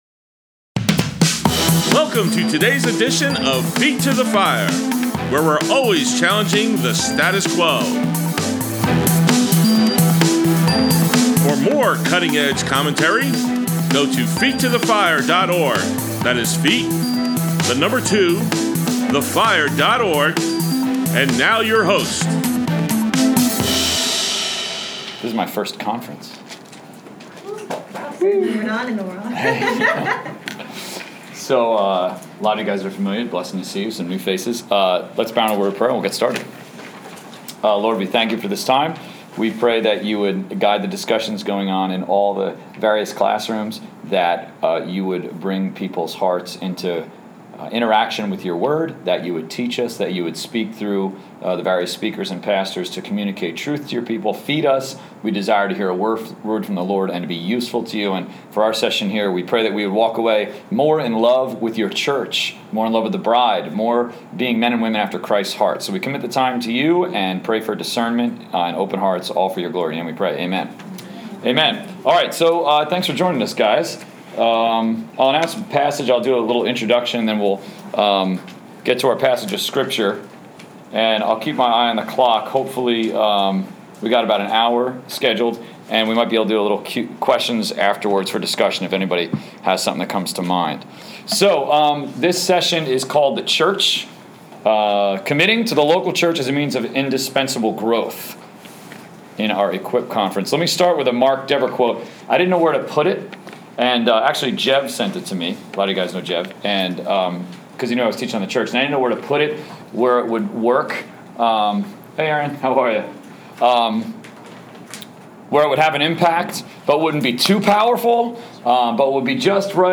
Brookdale CRU Equip Conference at Lincroft Bible Church